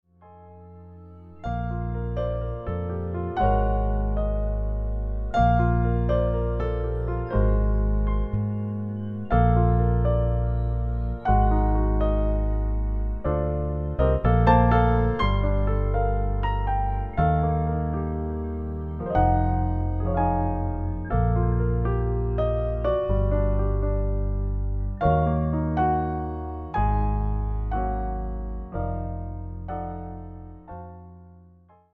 piano and bass
Cool and classy lounge sounds